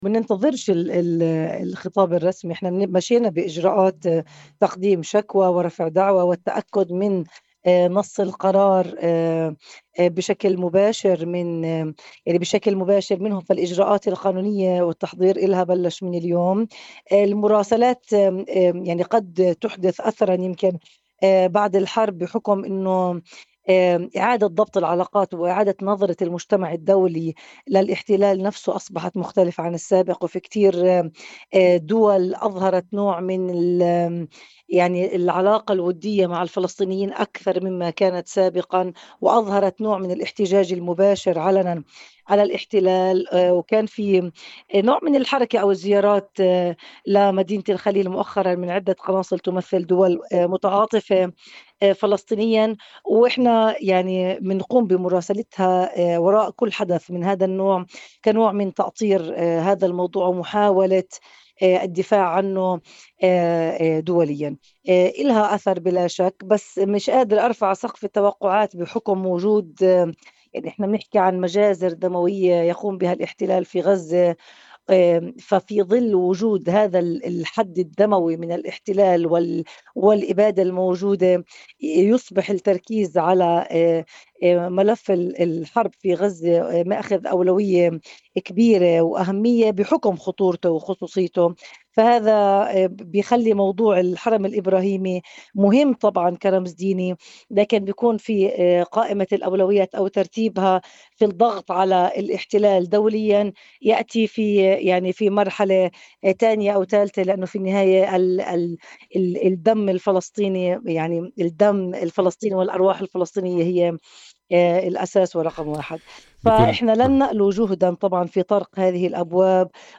نائبة رئيس بلدية الخليل لإذاعة “قناة القدس”: الاحتلال ينفذ خطوات غير مسبوقة لتغيير الوضع القائم في الحرم الإبراهيمي